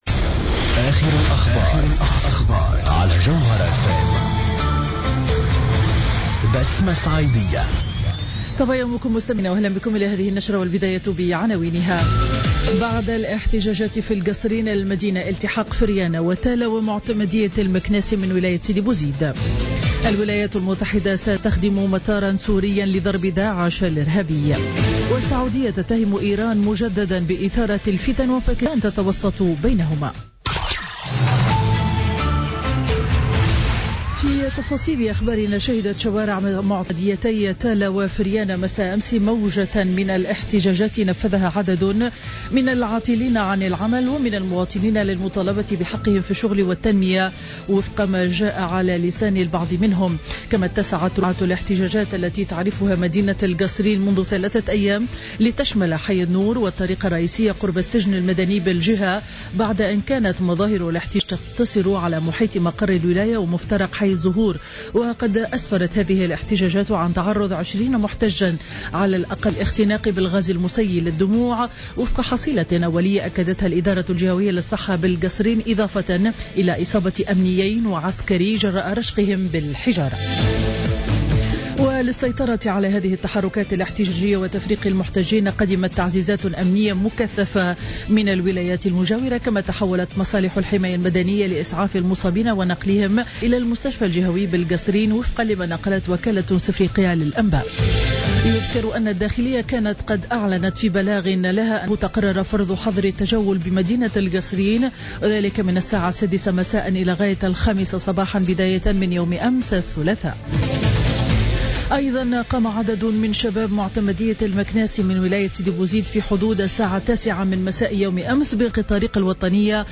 نشرة أخبار السابعة صباحا ليوم الأربعاء 20 جانفي 2016